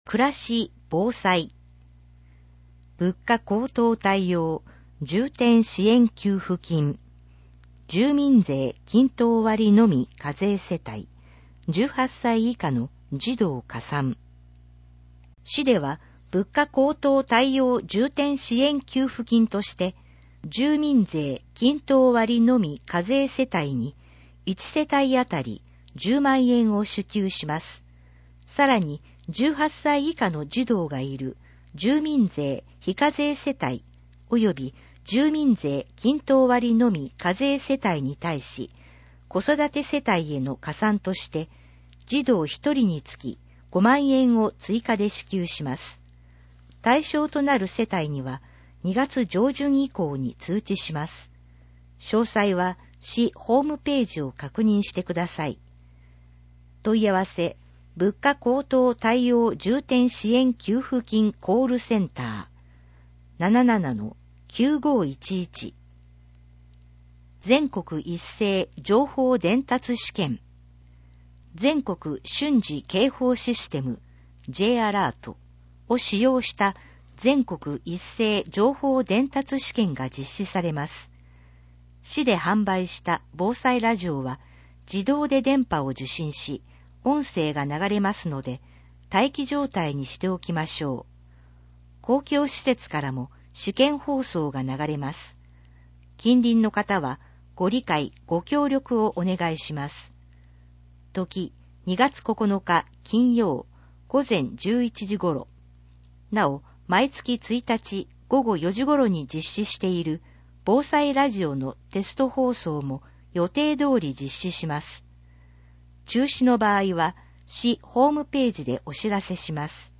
以上の音声データは、「音訳ボランティア安城ひびきの会」の協力で作成しています